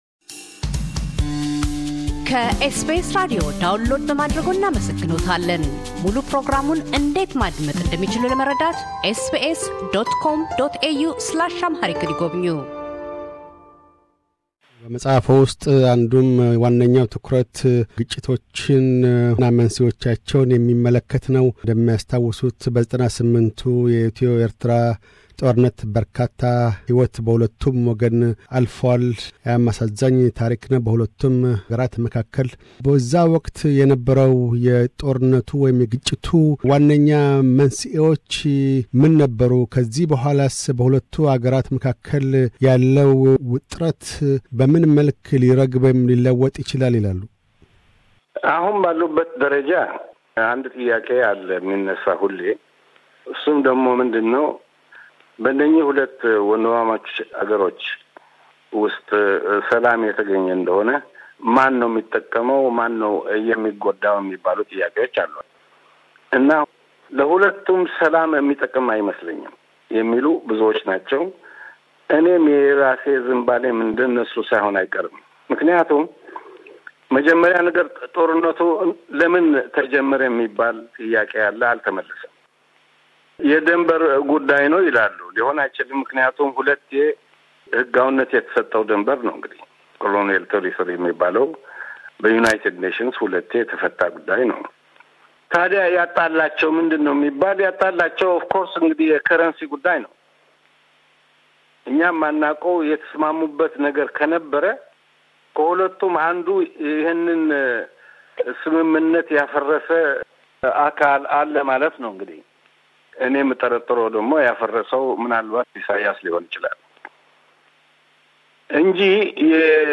Interview with Herui Tedla Bairu – Pt 2